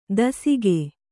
♪ dasige